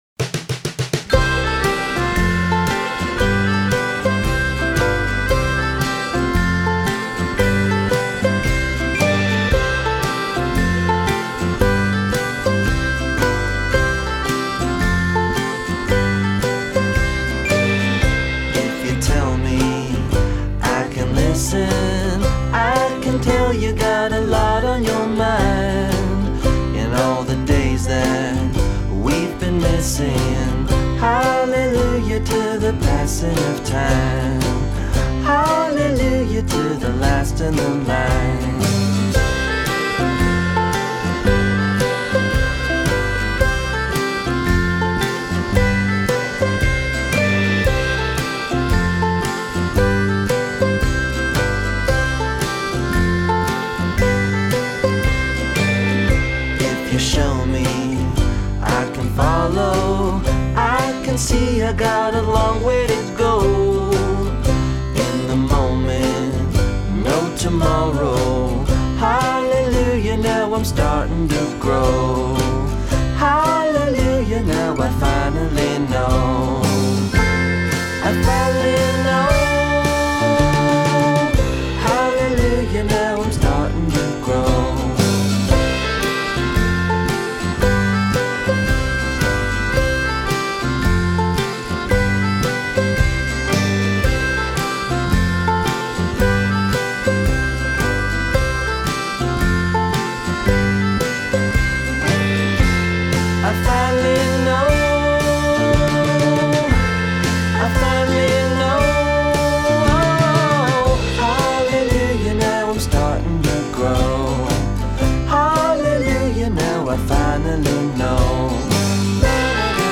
in a holiday spirit